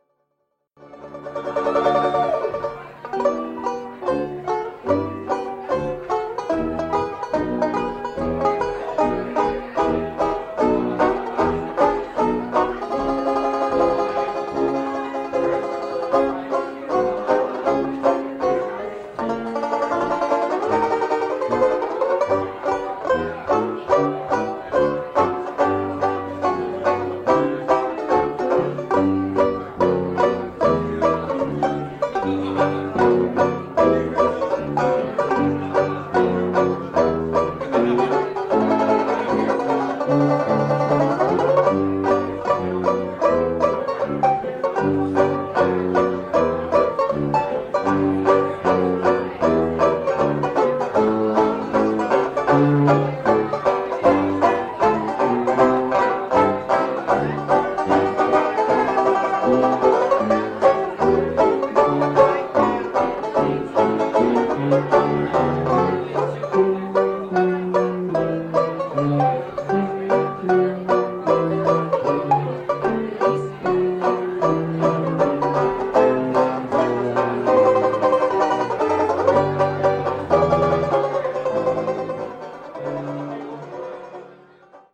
Recorded at 39 Main Restaurant in Tiburon, CA - 1975